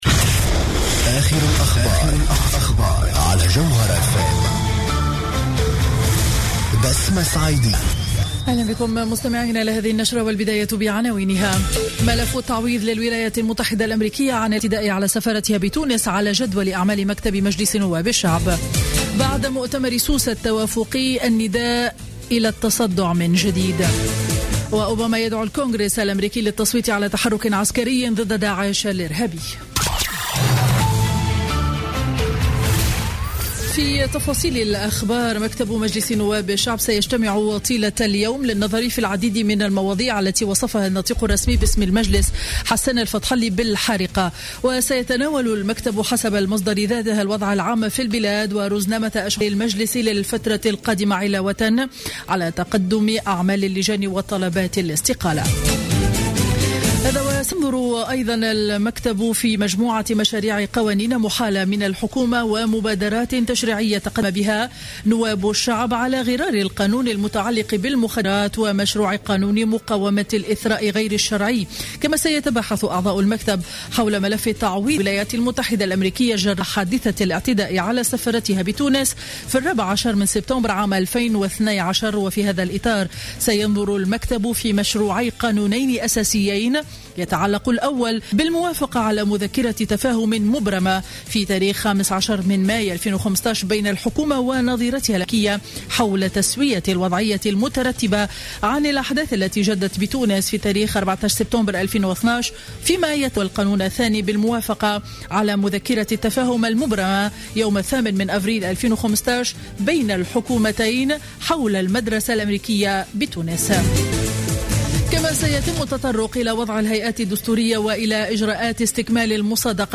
نشرة أخبار منتصف النهار ليوم الأربعاء 13 جانفي 2016